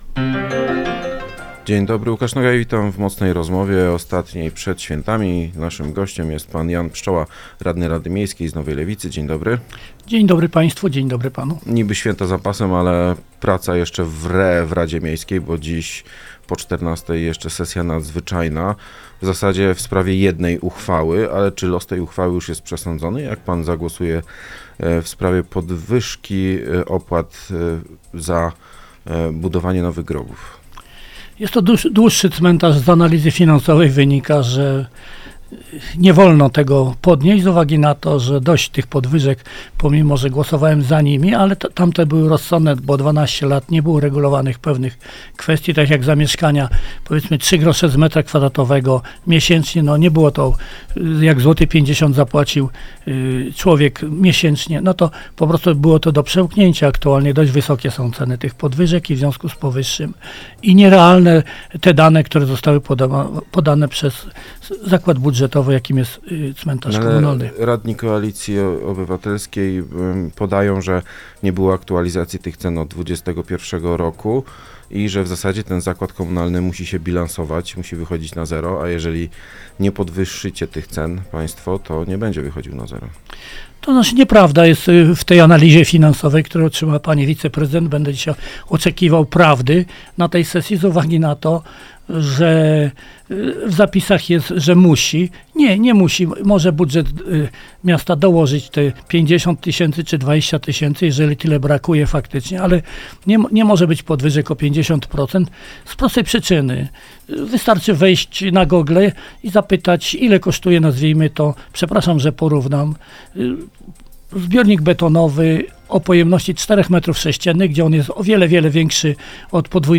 Jan Pszczoła, radny Nowej Lewicy był gościem